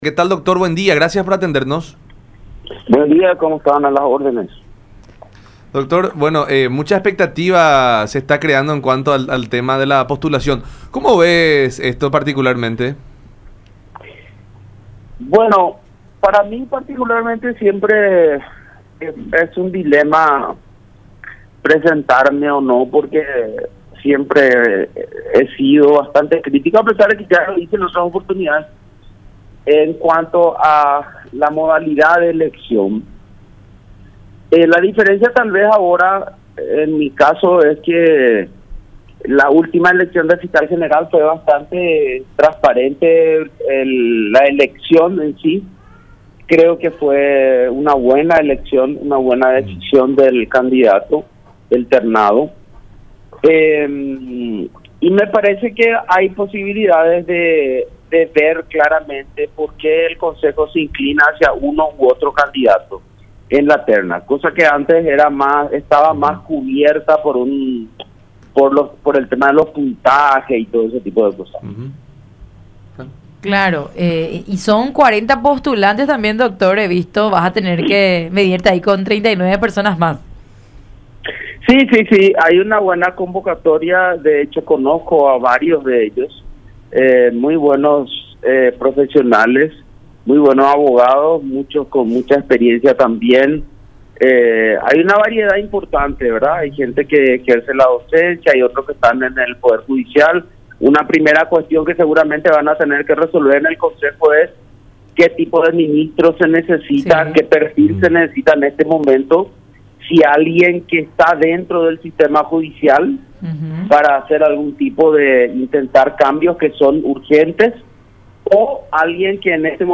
en diálogo con La Unión Hace La Fuerza a través de Unión TV y radio La Unión